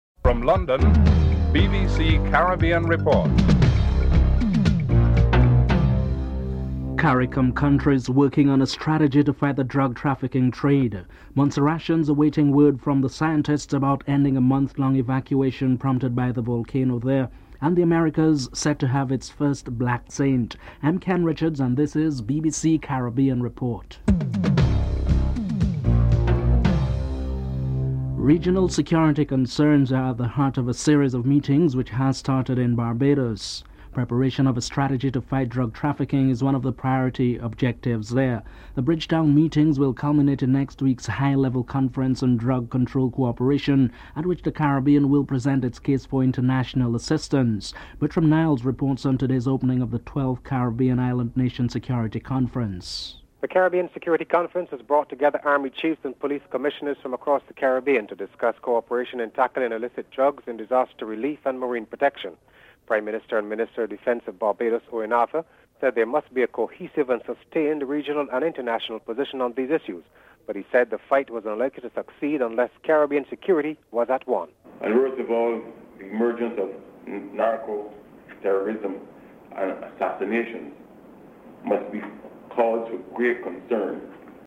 4. Montserratians awaiting words from the scientist about ending a month long evacuation prompted by the volcano there. Governor Frank Savage interviewed